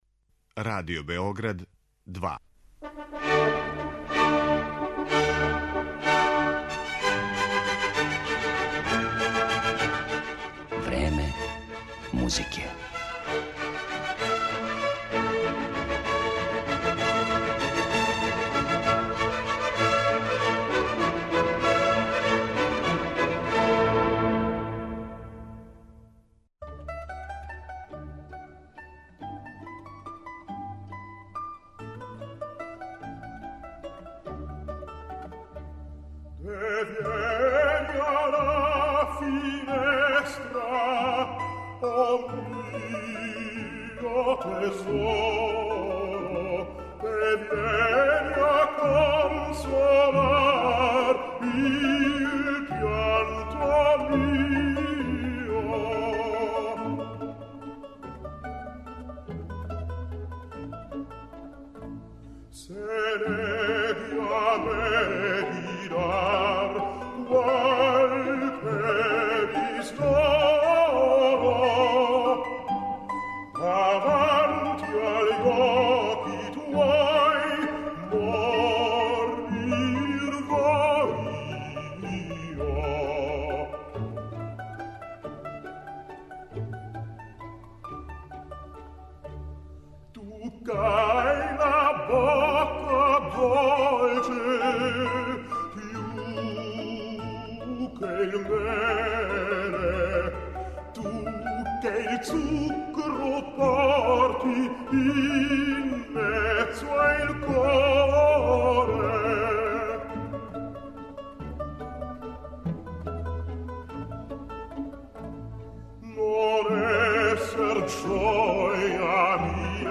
Oперске арије